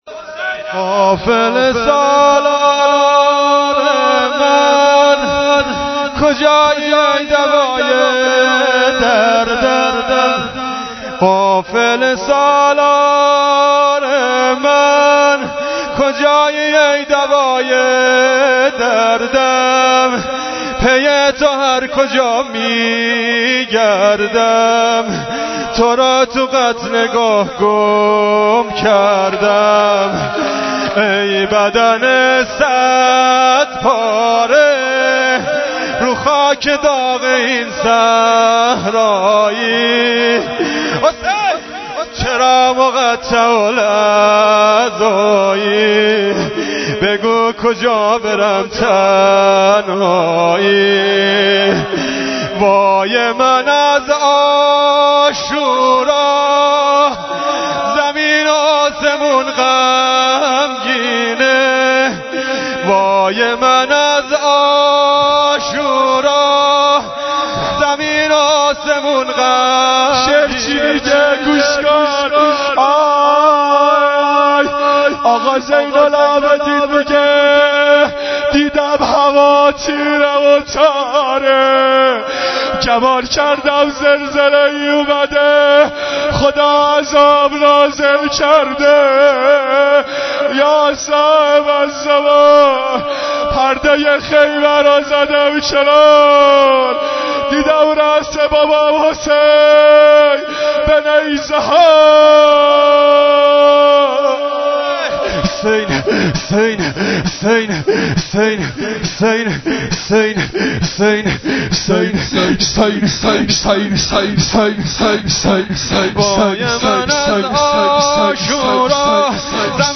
ظهر عاشورا شور